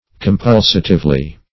Compulsatively \Com*pul"sa*tive*ly\, adv.
compulsatively.mp3